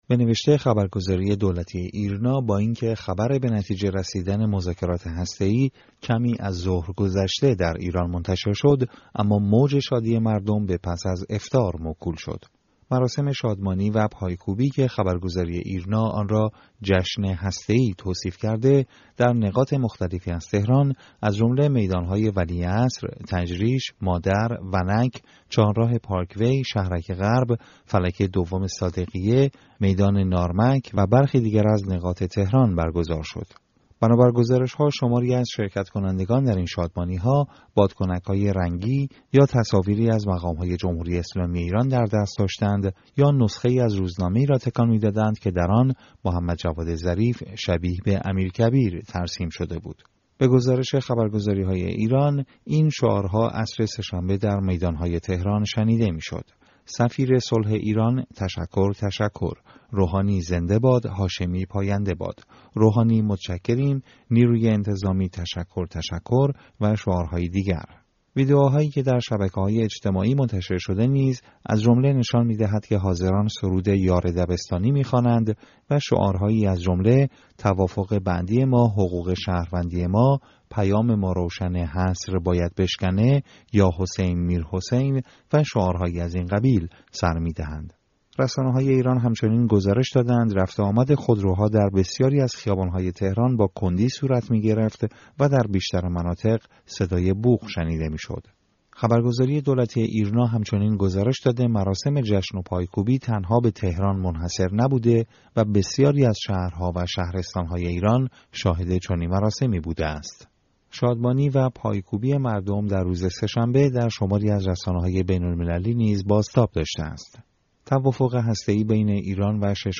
ساعاتی پس از انتشار خبر توافق هسته‌ای، مردم در تهران و دیگر شهرهای ایران به خیابان‌ها آمدند و به جشن و پایکوبی پرداختند.
شرکت‌کنندگان در این شادمانی‌ها، شعارهایی به ویژه در حمایت از محمدجواد ظریف، وزیر خارجه ایران، سر می‌دادند و همچنین خواستار پایان حصر میرحسین موسوی ، زهرا رهنورد و مهدی کروبی شدند:
شادی مردم در خیابان‌ها پس از اعلام توافق هسته‌ای